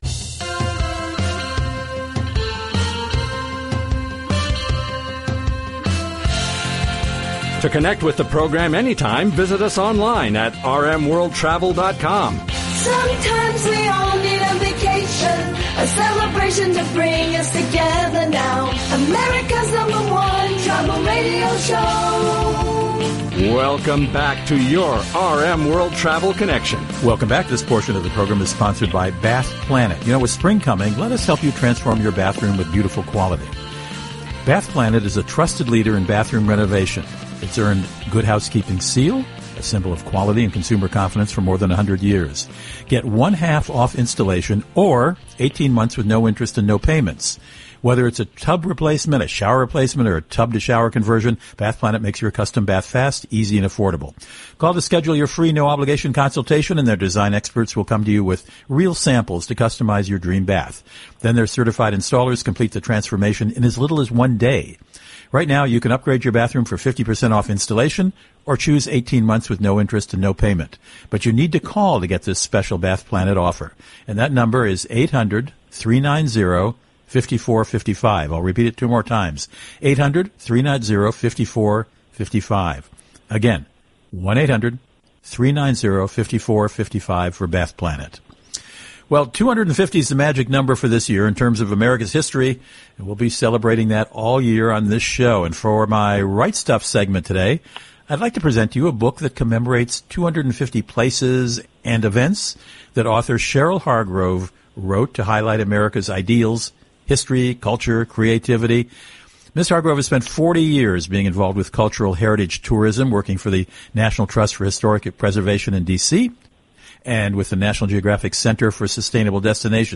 Now that the program has been transmitted over satellite and aired across the USA via our 615 weekly AM & FM radio stations, you can access the interview again that’s been archived here by clicking the play arrow immediately following this text … https